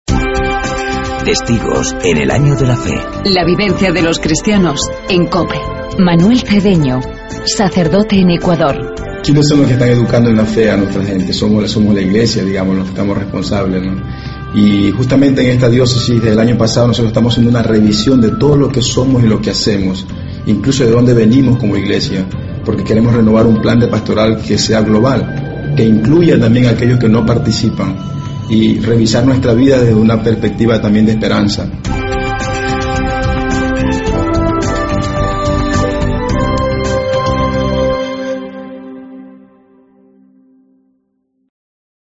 Escuchamos el testimonio del sacerdote ecuatoriano